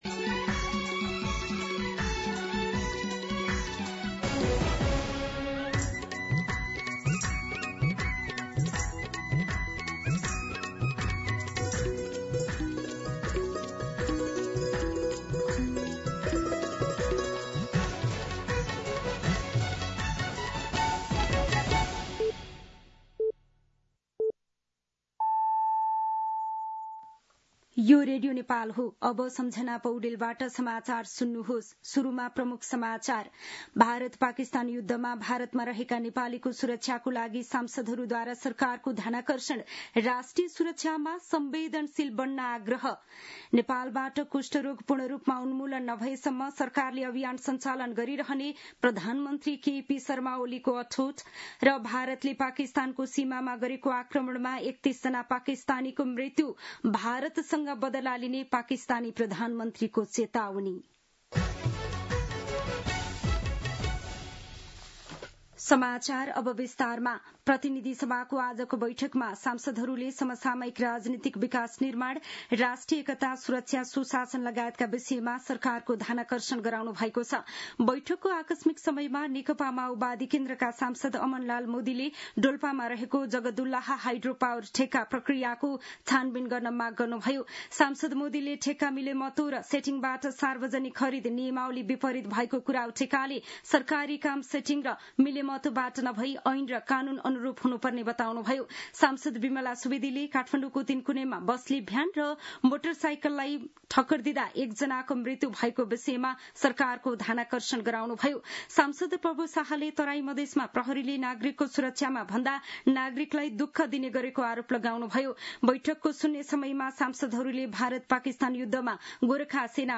दिउँसो ३ बजेको नेपाली समाचार : २५ वैशाख , २०८२
3-pm-Nepali-News-1.mp3